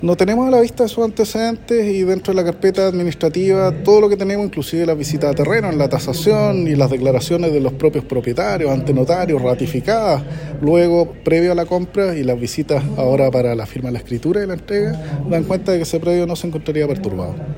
El director nacional (s) de Conadi, Álvaro Morales, dijo a la radio que no tenían conocimiento de esas medidas y que se realizaron visitas para confirmar que el predio no estuviera “perturbado”.